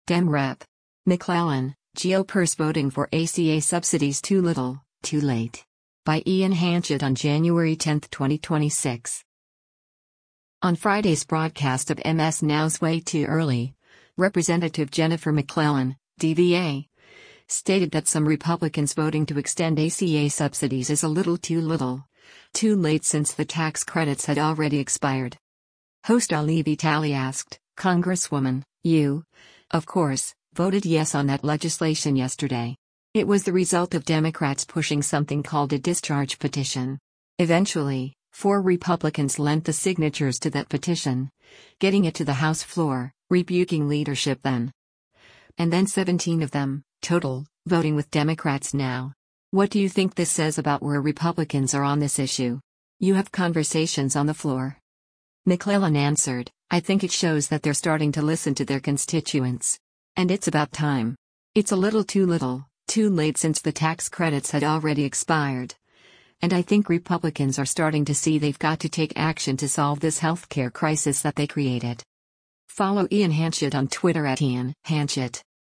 On Friday’s broadcast of MS NOW’s “Way Too Early,” Rep. Jennifer McClellan (D-VA) stated that some Republicans voting to extend ACA subsidies is “a little too little, too late since the tax credits had already expired.”